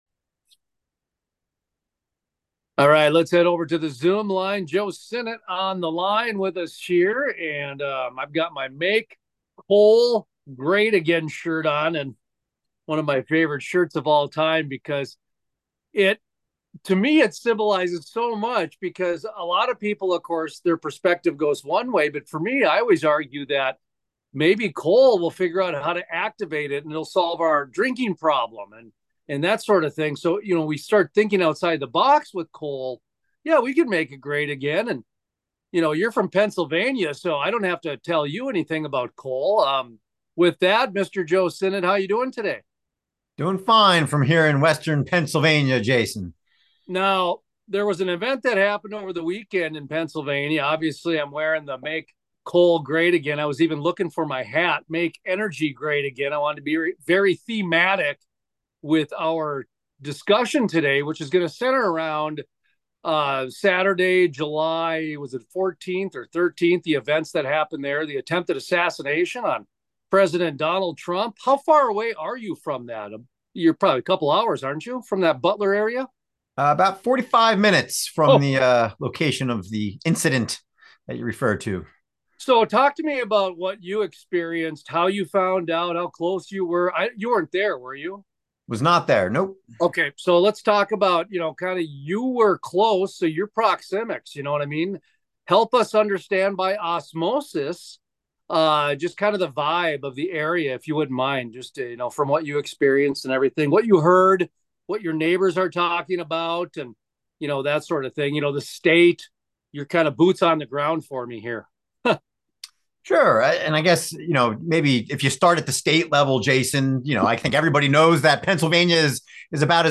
Full Length Interviews Are You Unwittingly Enabling Chaos?